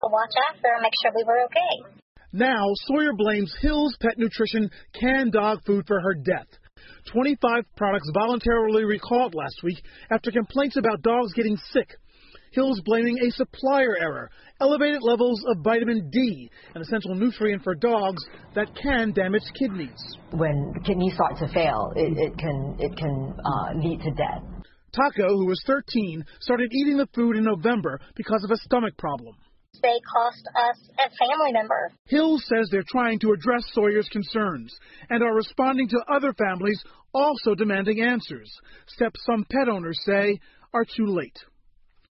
NBC晚间新闻 问题狗粮致宠物死亡 听力文件下载—在线英语听力室